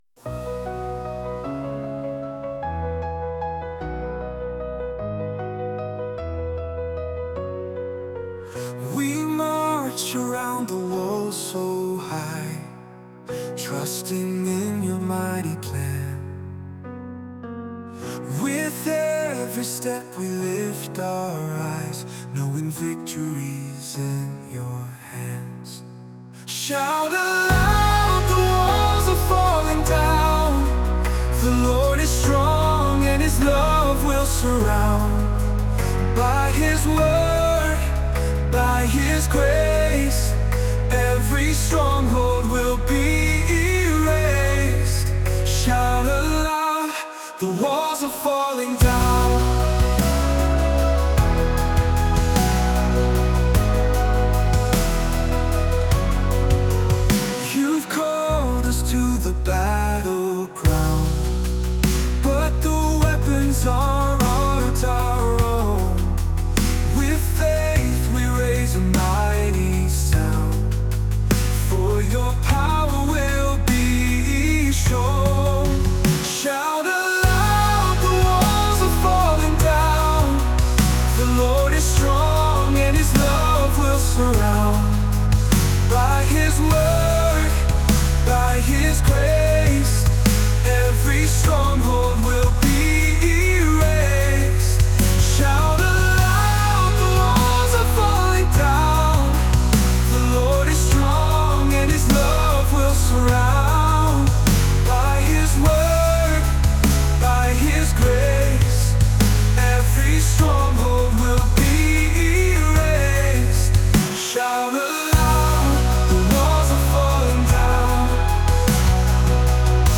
Christian Praise